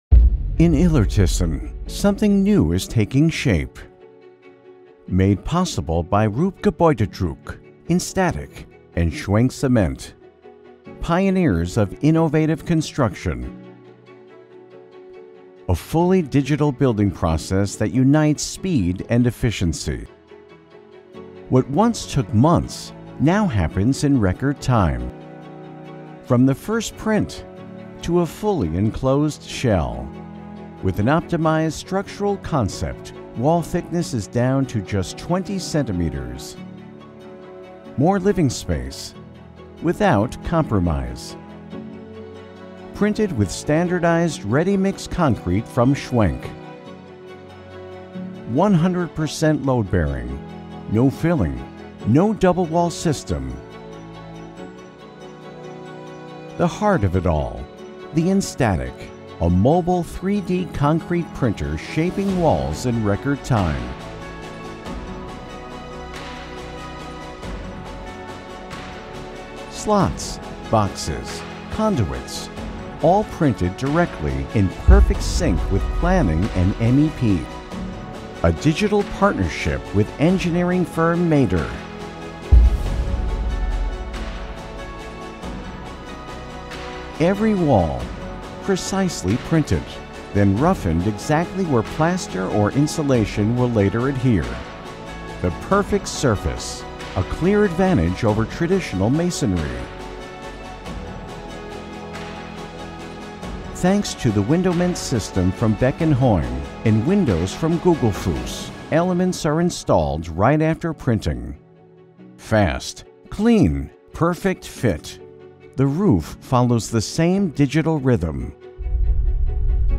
Erzählung
My style of VO is bright, clear and articulate.
My voice can be described as clear, friendly, personable, confident, articulate, and smooth.
Sennheiser 416 mic